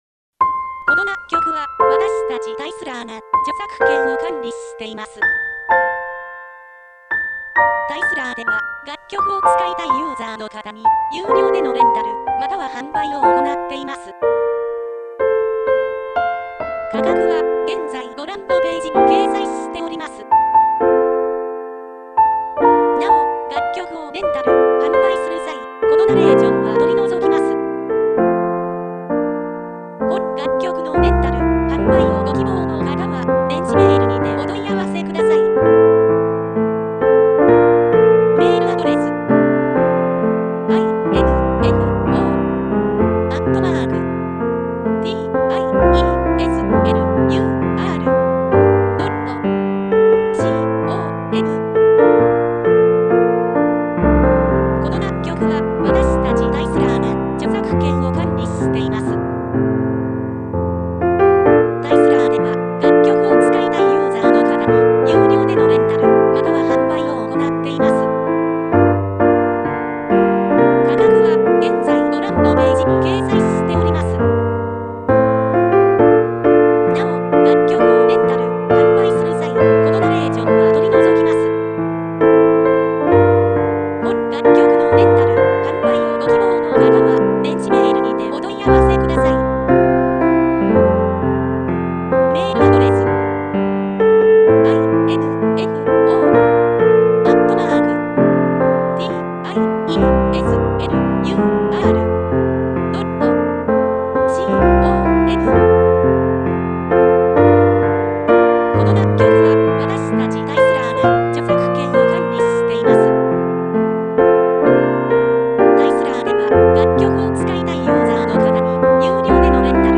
■ピアノ曲のレンタル・販売ページ
●メジャーキー・スローテンポ系